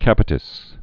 tinea cap·i·tis
(kăpĭ-tĭs)